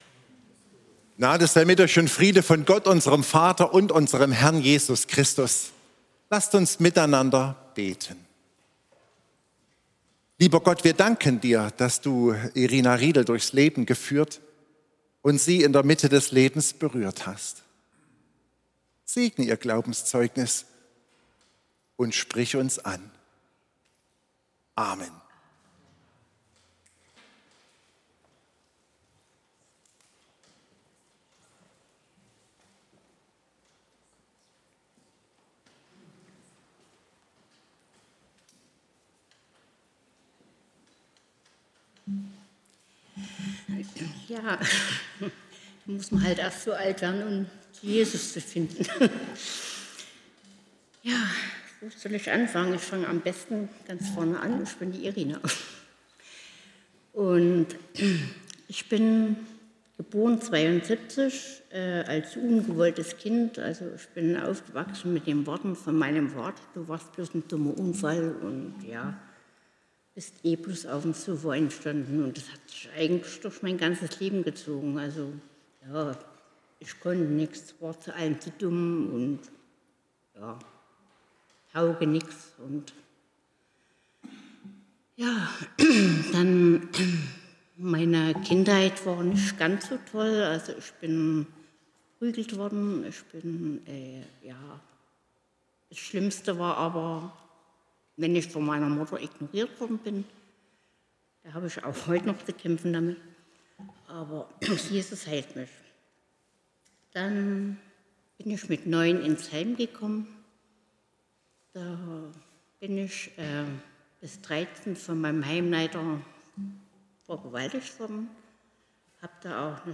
Lesung: Johannes 2, 1-11